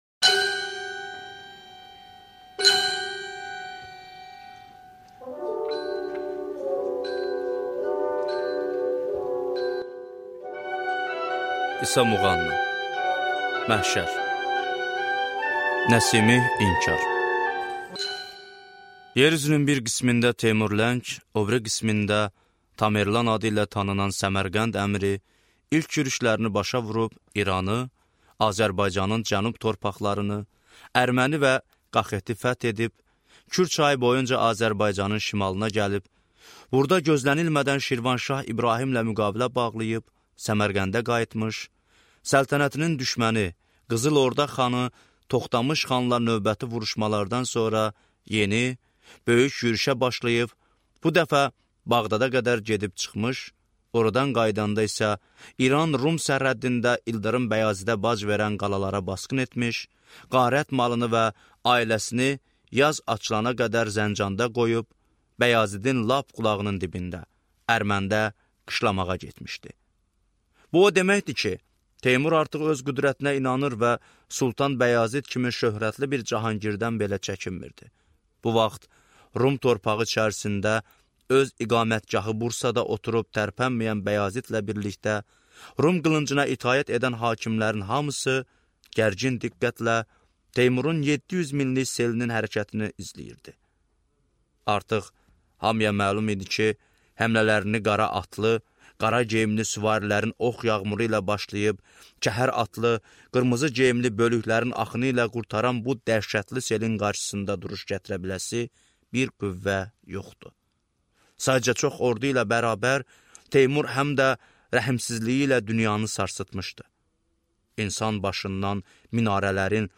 Аудиокнига Məhşər | Библиотека аудиокниг